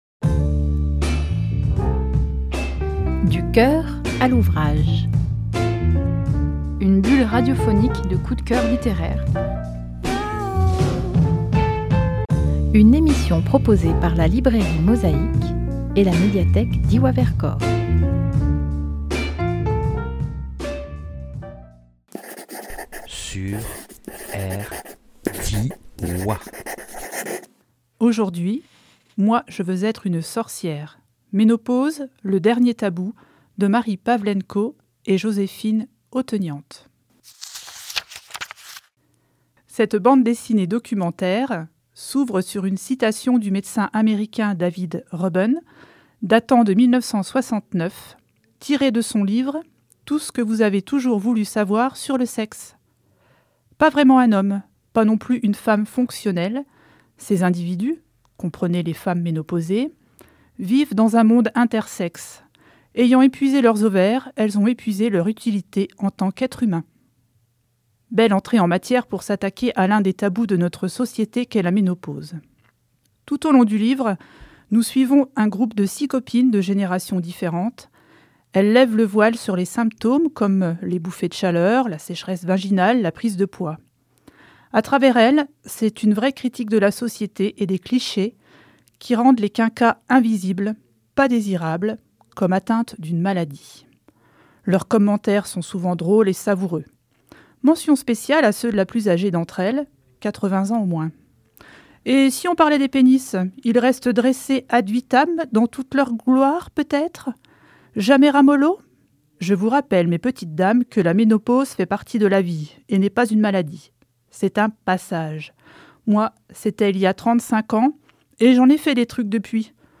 Studio Rdwa – avril 24